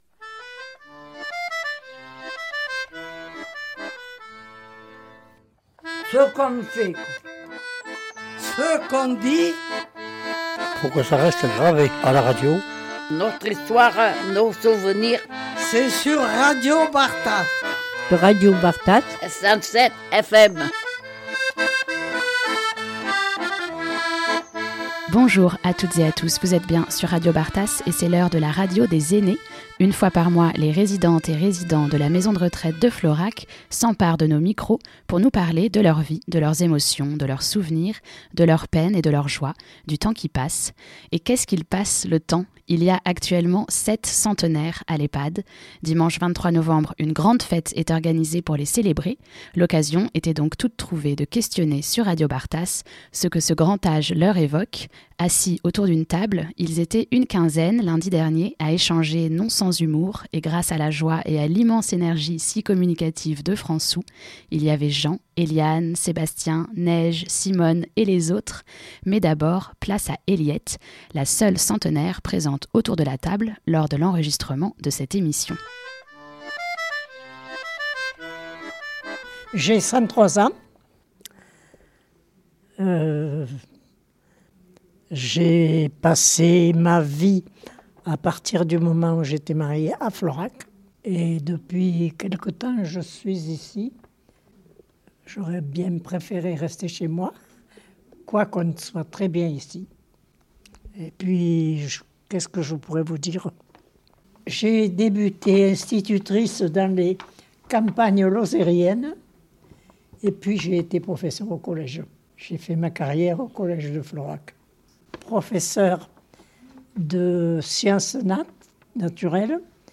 Une fois par mois, les résidentes et résidents de la maison de retraite de Florac s’emparent de nos micros pour nous parler de leur vie, de leurs émotions, de leurs souvenirs, de leurs peines et de leur joie, du temps qui passe…